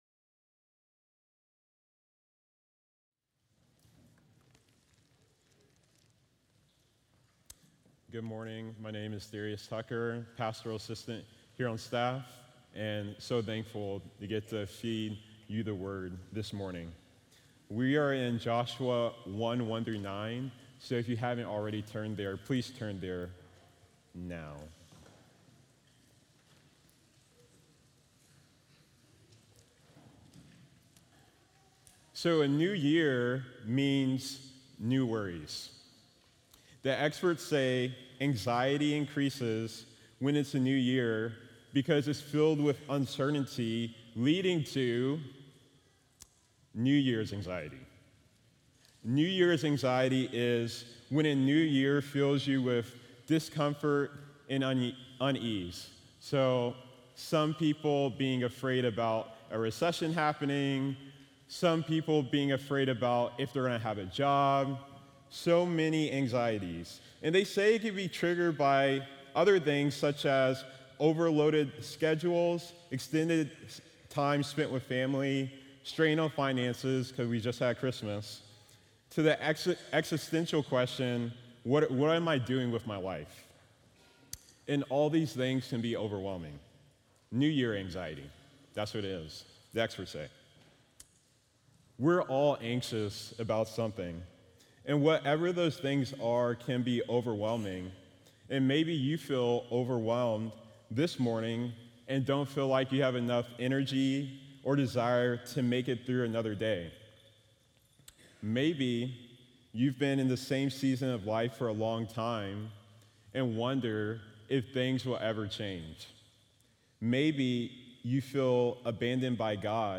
Guest Preachers
Video & Sermon Audio